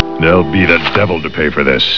From Spider-Man: The Animated Series.